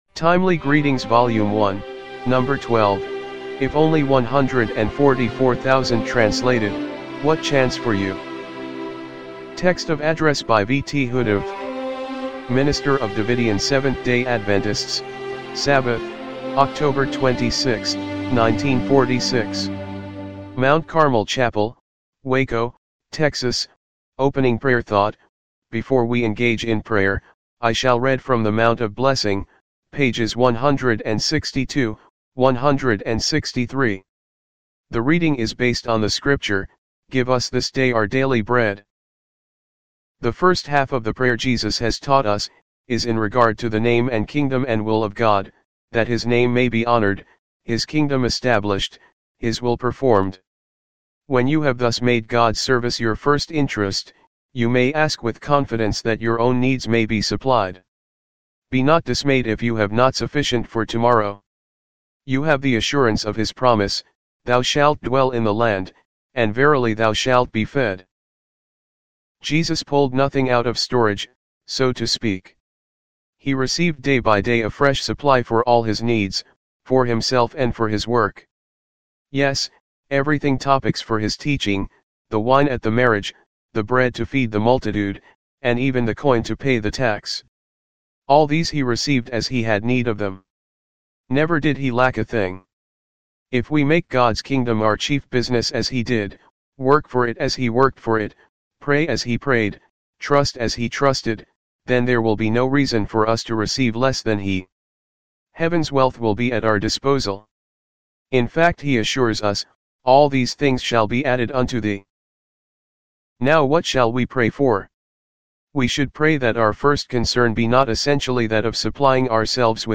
timely-greetings-volume-1-no.-12-mono-mp3.mp3